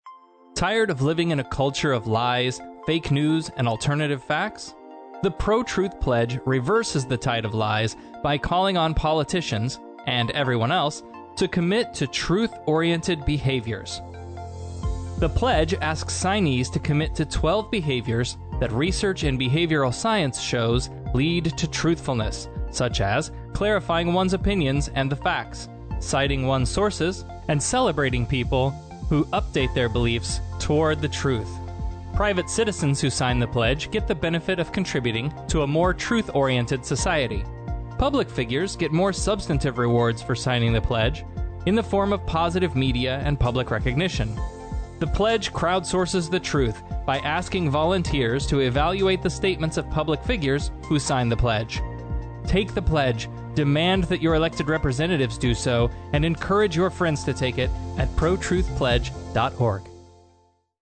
PSAs for Podcasts and Radio Shows